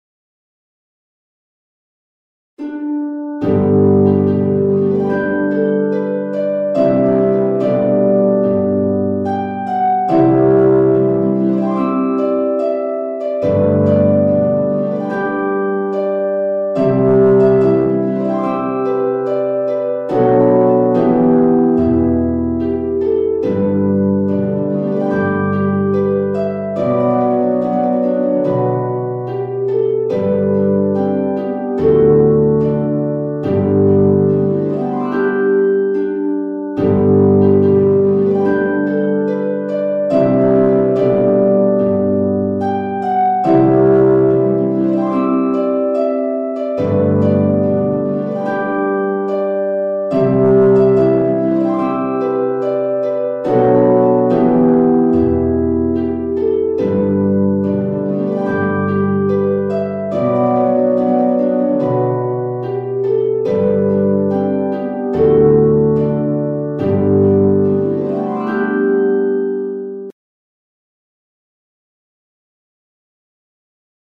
traditional
Parts 1 and 2 Arranged for Beginner
LEVER HARP or PEDAL HARP
Part 1 is the melody of the tune.